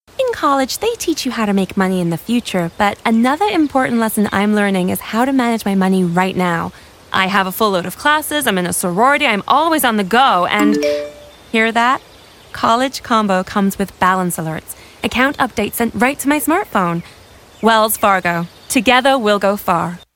Commercial V/O Wells Fargo - Standard US Accent
Bright, Excited, College Student